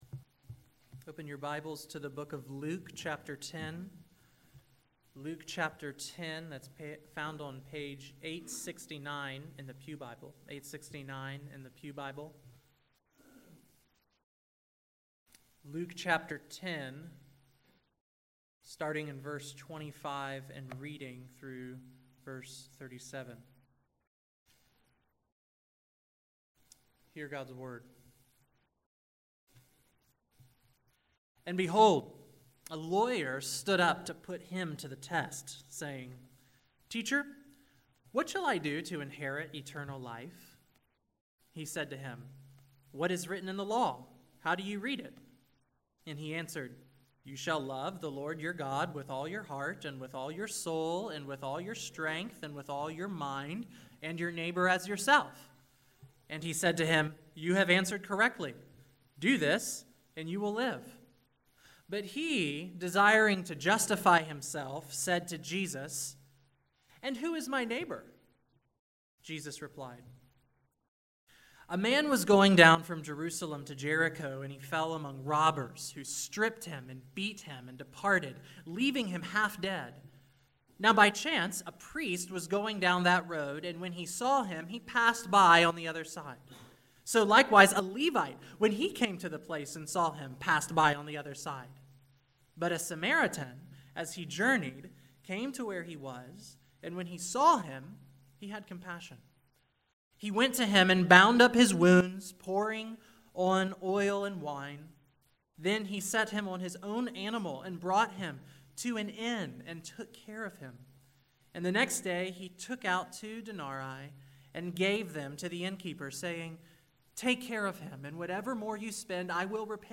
July 23, 2017 Morning Worship | Vine Street Baptist Church